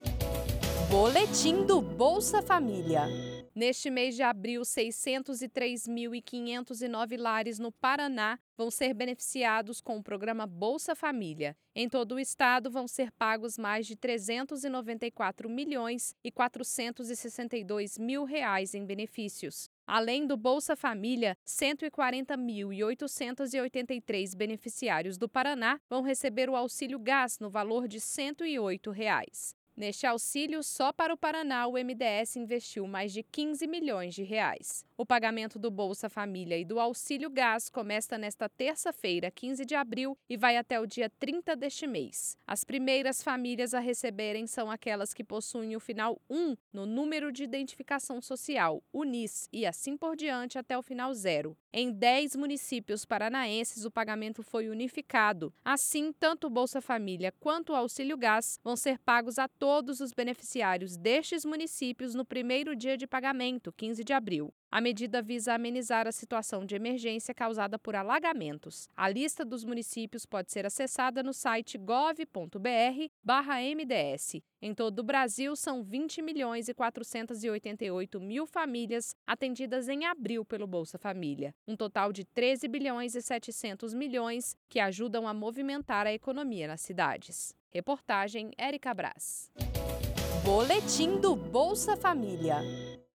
Boletim sobre o calendário de pagamento do Bolsa Família e do Auxílio Gás em abril de 2025.